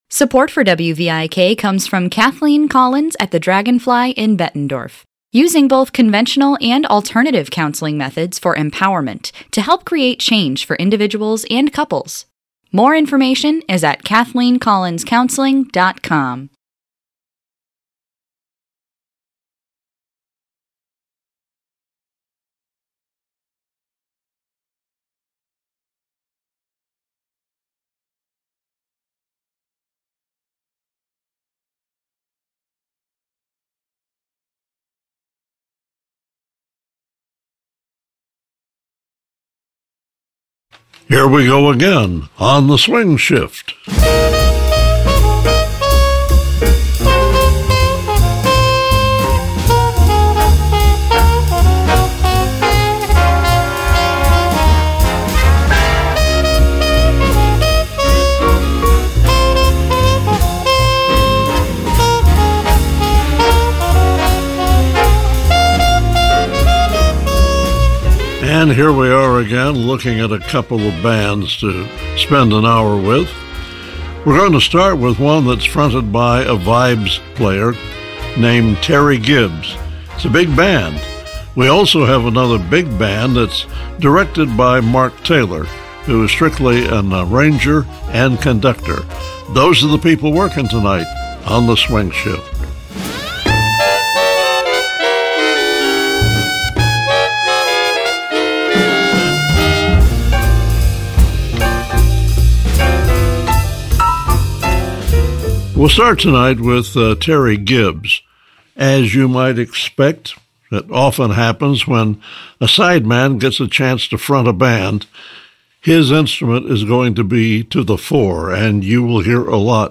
swing-era favorites